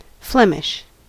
Ääntäminen
US : IPA : [ˈflɛm.ɪʃ]